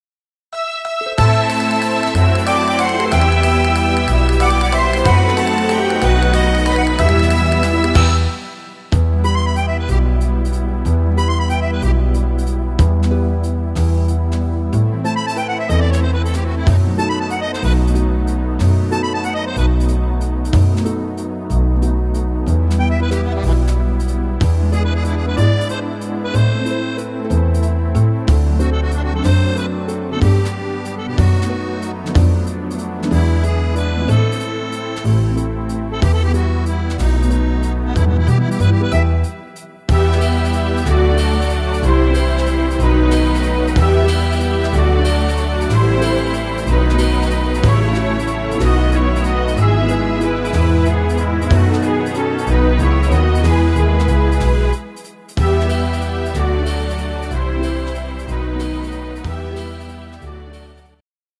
Musette (instr. Akkordeon